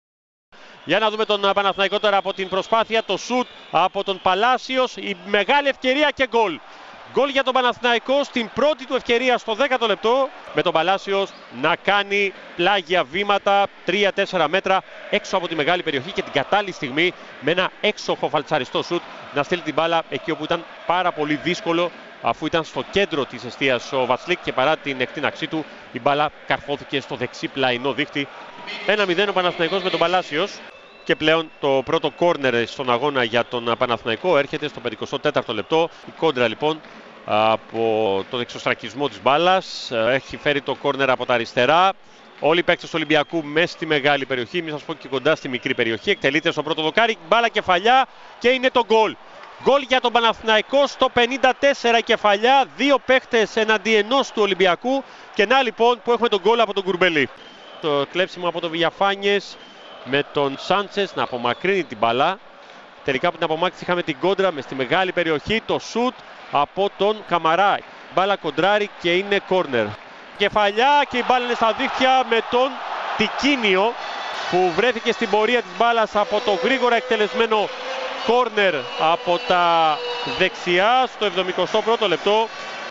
ΕΡΑΣΠΟΡ: Η περιγραφή των γκολ της 8ης αγωνιστικής των πλέι οφ (audio)